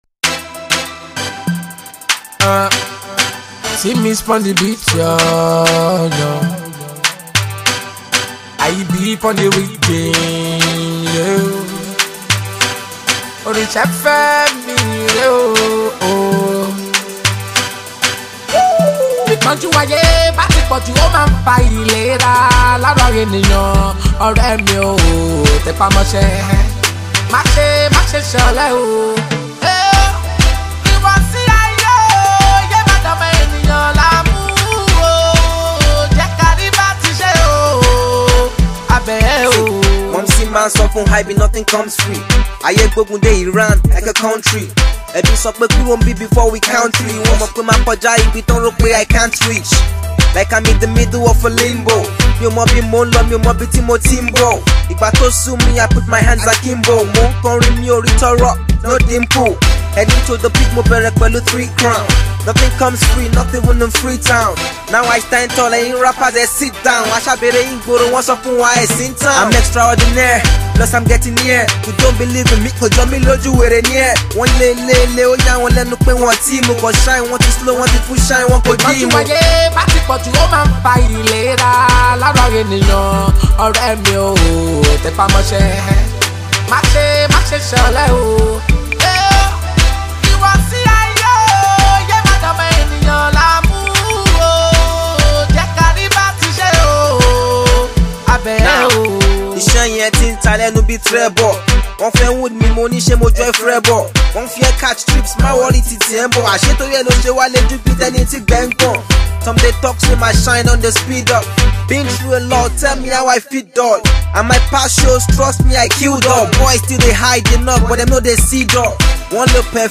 indigenous Hip-Hop, Yoruba Music